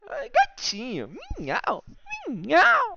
gatinhomiau.mp3